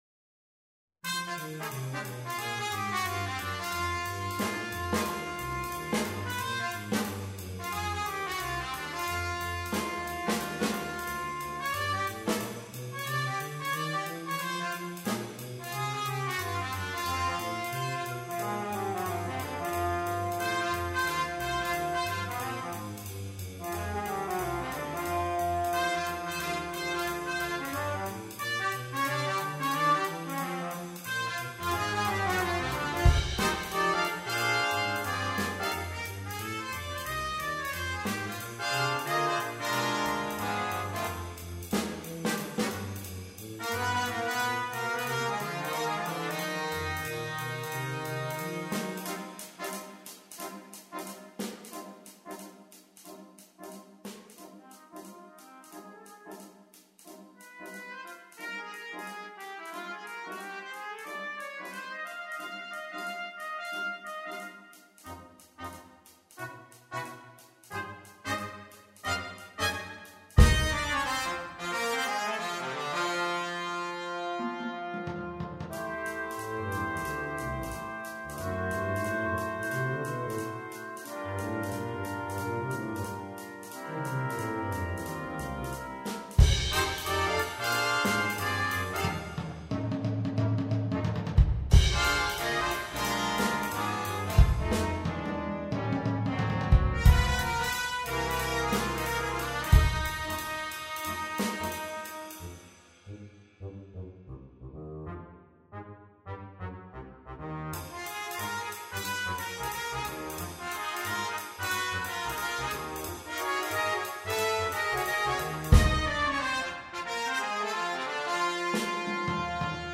Brass Quintet (optional Percussion)
Drum part with written fills included.